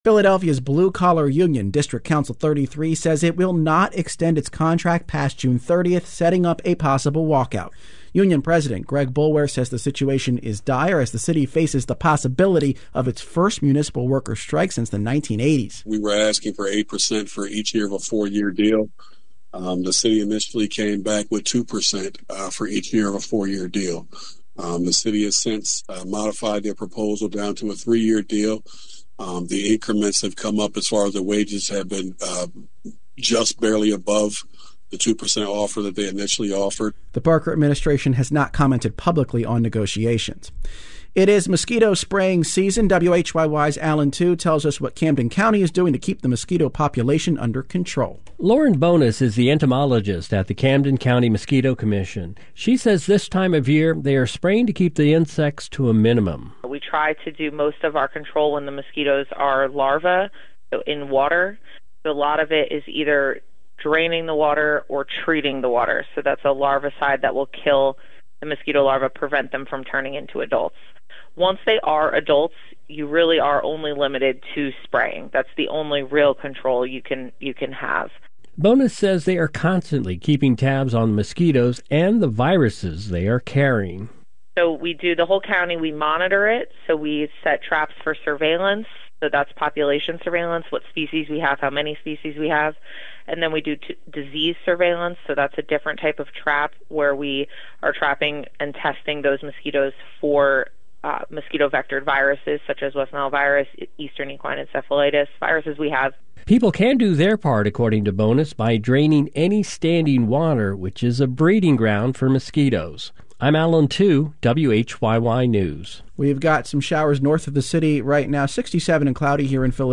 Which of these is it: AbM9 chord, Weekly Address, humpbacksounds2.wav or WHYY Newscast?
WHYY Newscast